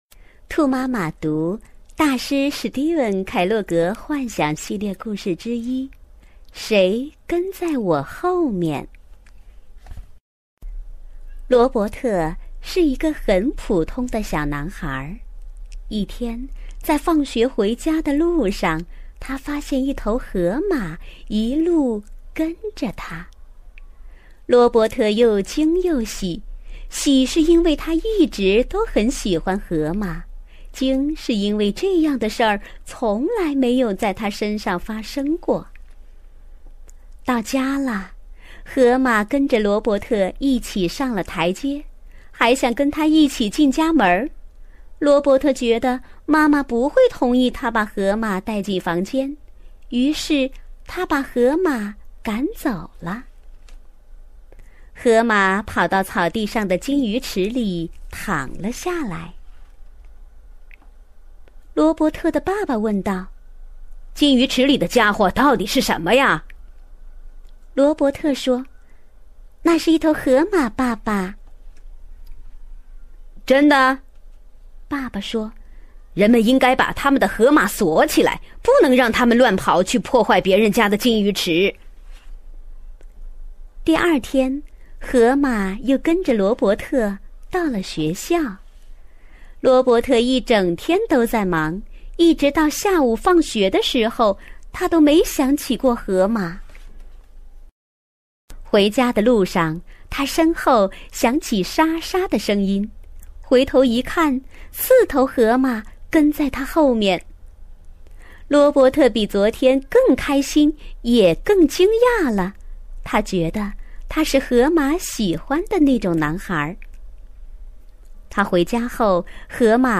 首页>mp3 > 儿童故事 > 谁跟在我后面(1)(神奇的蝌蚪)